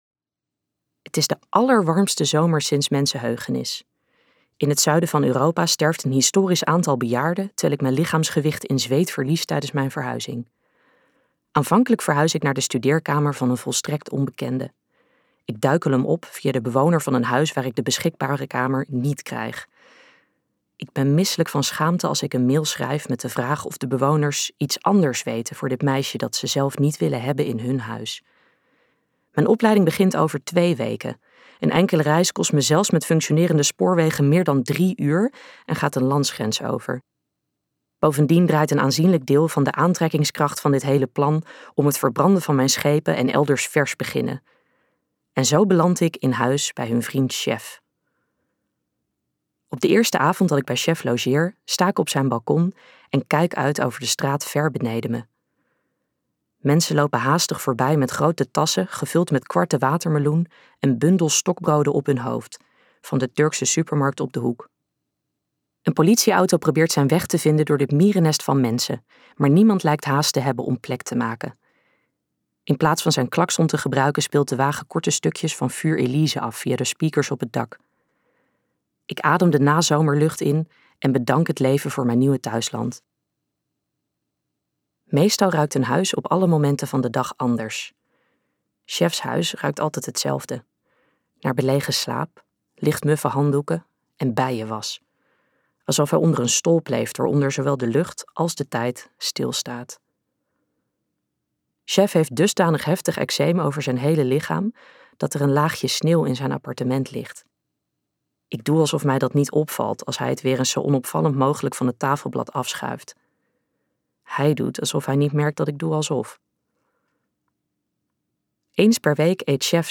Ambo|Anthos uitgevers - De bananenlezers luisterboek